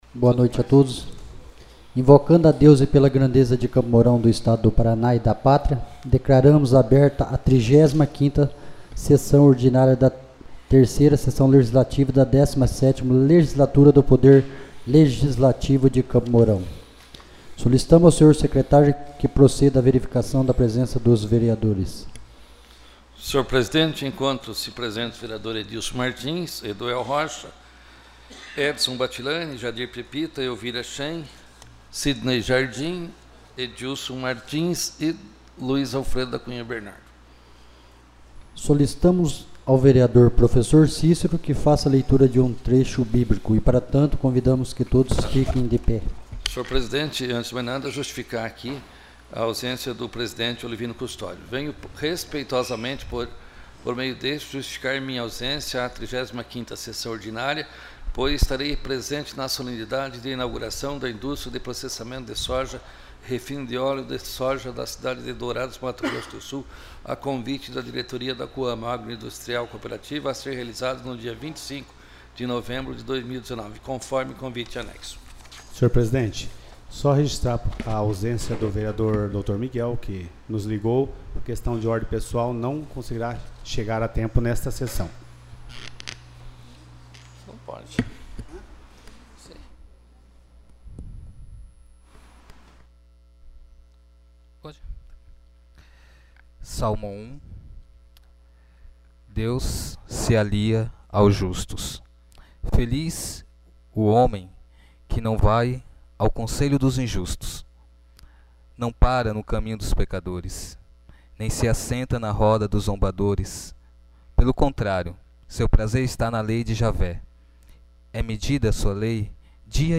35ª Sessão Ordinária